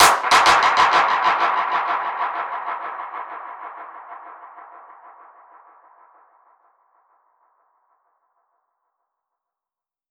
DPFX_PercHit_C_95-06.wav